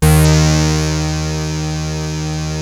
OSCAR D#3 2.wav